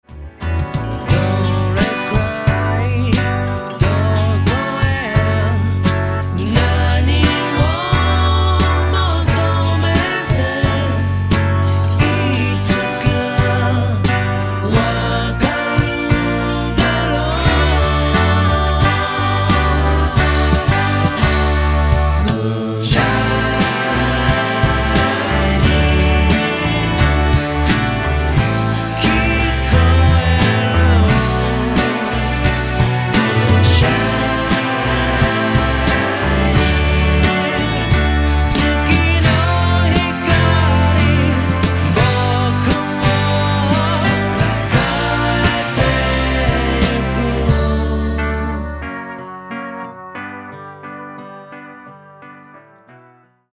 The first time the backing vocals come in is the most obvious example.